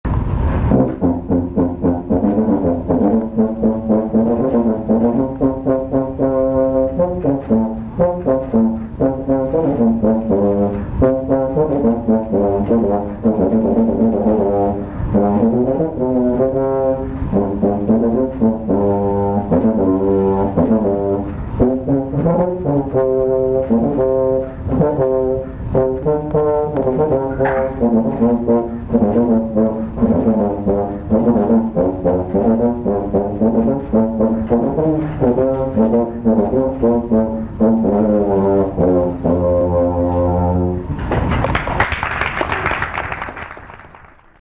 Douglas Yeo plays the serpent
Handel - La Rejouissance (from "Music for the Royal Fireworks"). Recorded at Boston Museum of Fine Arts, April 30, 1998. Douglas Yeo, contrabass anaconda serpent in CC,"George," (Monk).